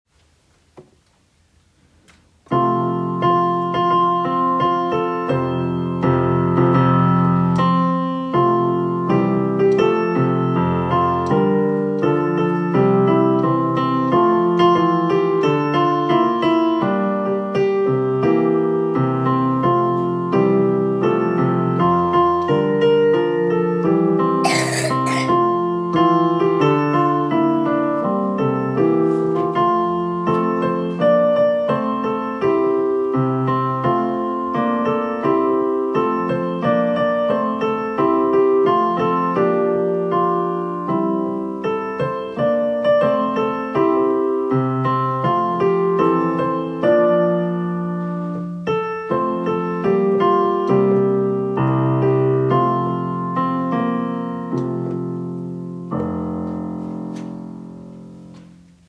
Voicing/Instrumentation: Primary Children/Primary Solo